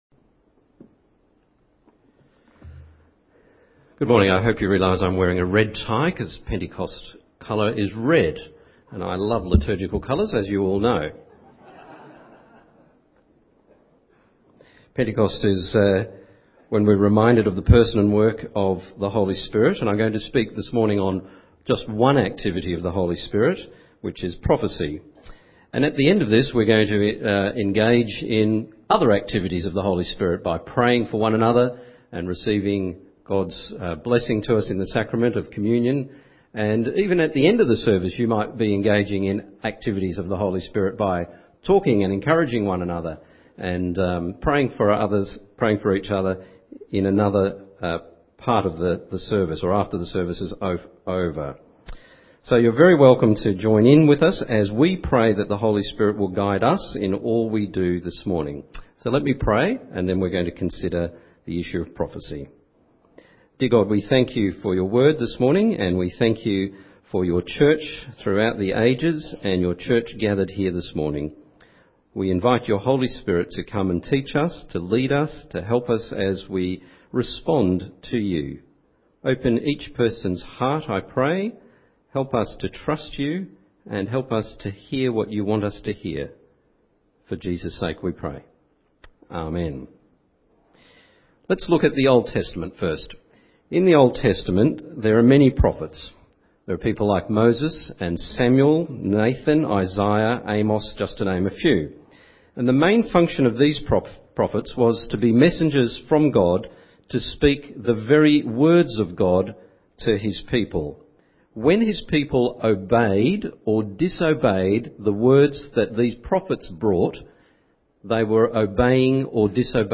Bible Passage